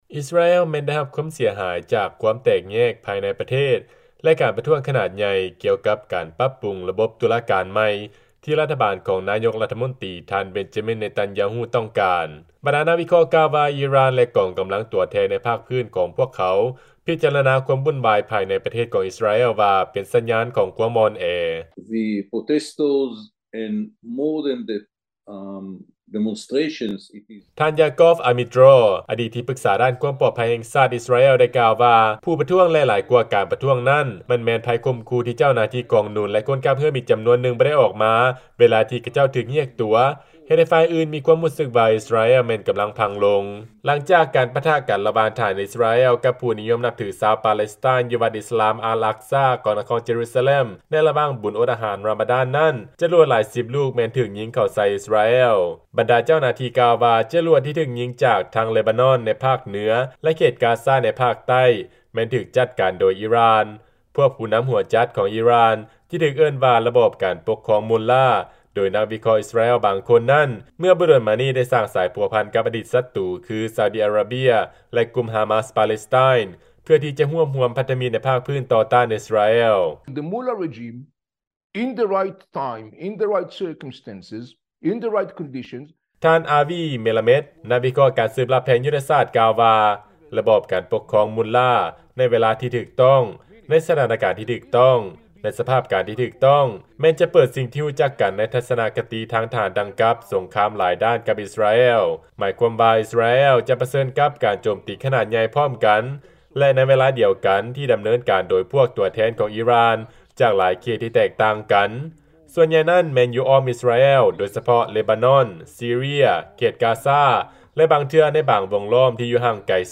ຟັງລາຍງານ ອິສຣາແອລ ເຕືອນຄວາມເຄັ່ງຕຶງກັບ ອີຣ່ານ ກຳລັງເພີ່ມຂຶ້ນ